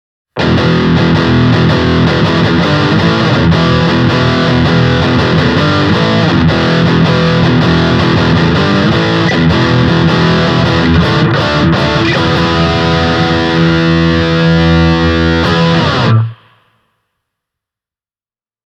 Akustisen kitaran mallintaminen onnistuu pikkukombolla yllättävän hyvin: